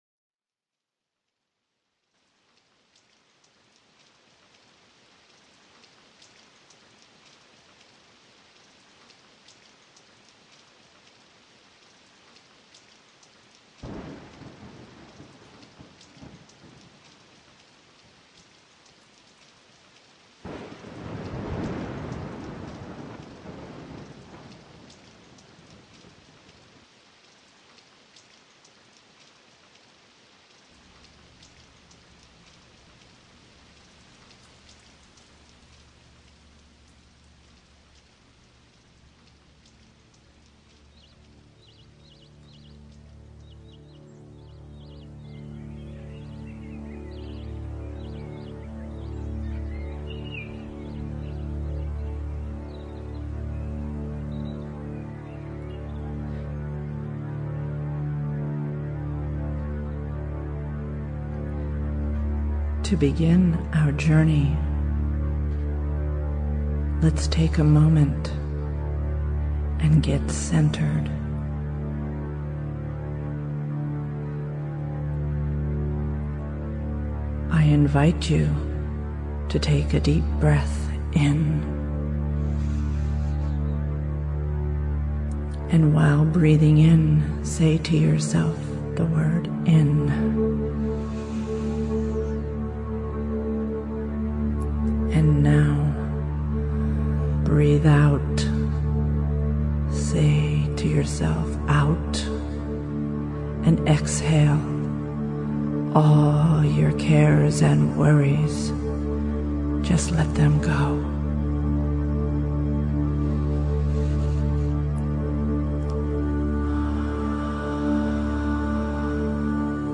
Reduce Stress & Anxiety and Calm Your Cluttered Mind with Sefira Meditation, a 20 minute Guided Meditation and Visualization
sefirameditation.mp3